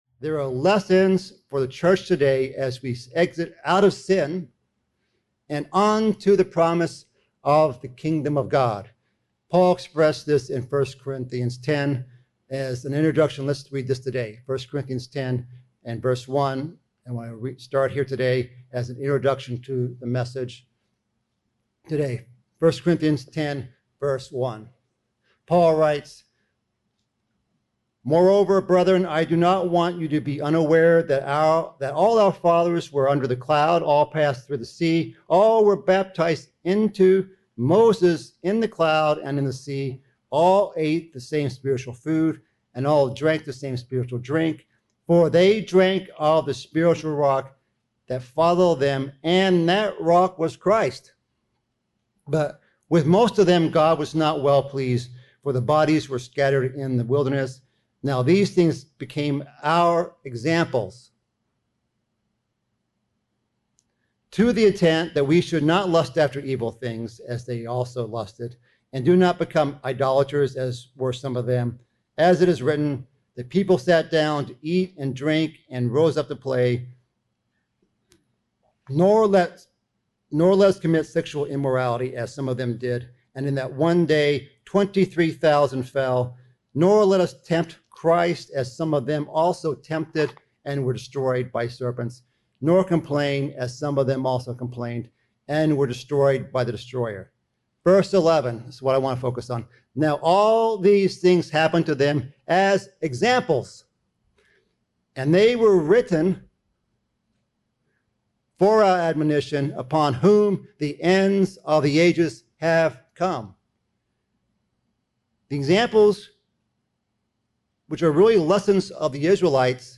Given in Beloit, WI